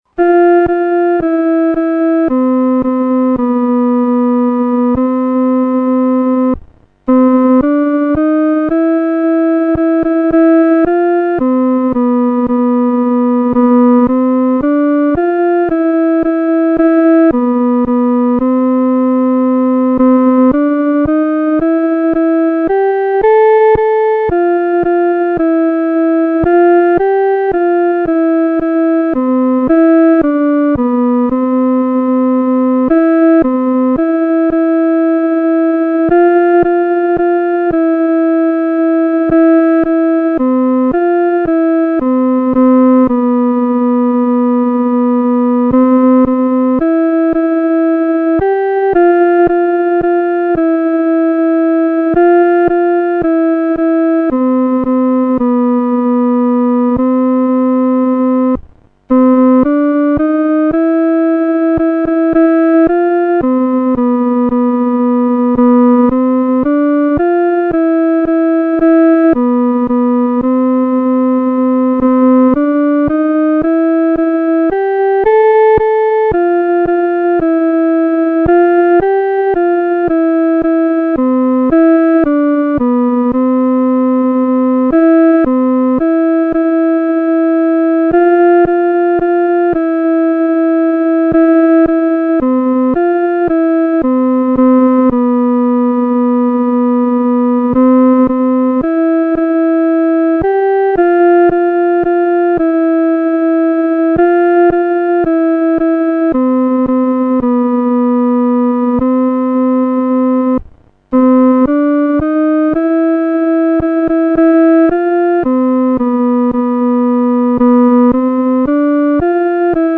独奏（第二声）
主翅膀下-独奏（第二声）.mp3